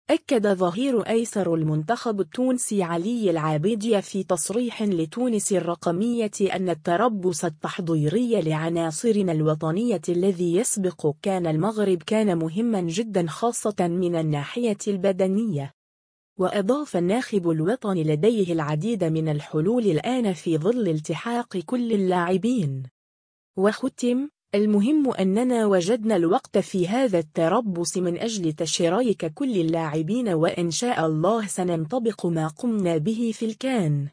أكّد ظهير أيسر المنتخب التونسي علي العابدي في تصريح لتونس الرقمية أنّ التربّص التحضيري لعناصرنا الوطنية الذي يسبق “كان” المغرب كان مهما جدا خاصة من الناحية البدنية.